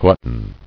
[glut·ton]